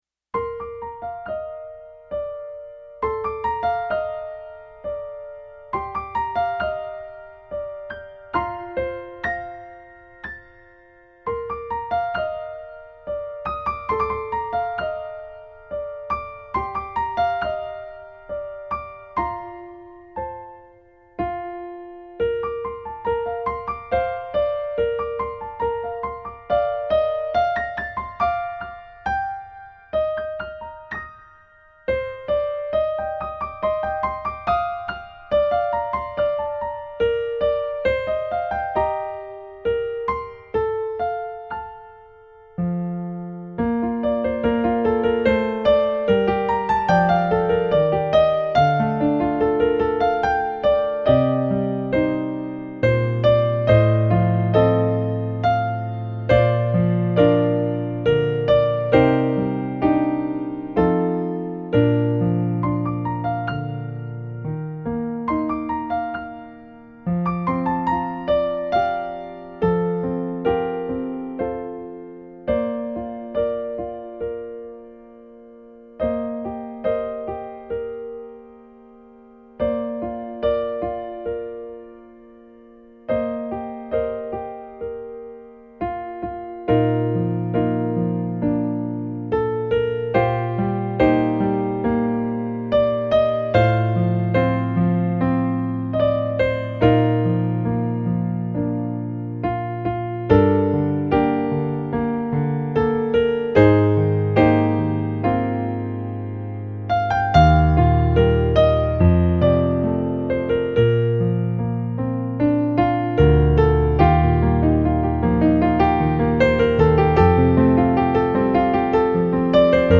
Christmas carols